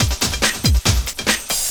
04 LOOP06 -L.wav